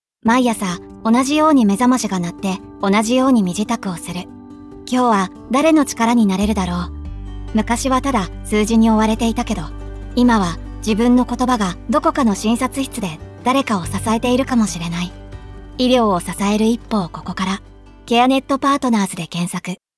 音声CM ケアネットパートナーズ採用プロモーション（株式会社ケアネットパートナーズ様）